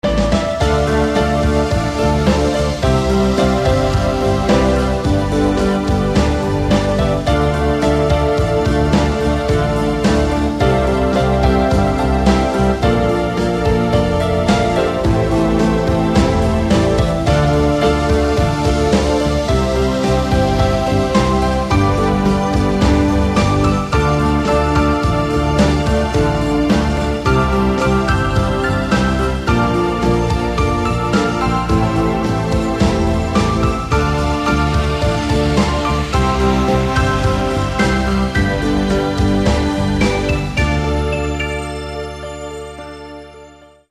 Slow rock ballad with heavy, emotional piano.